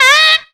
DOWN SQUEAL.wav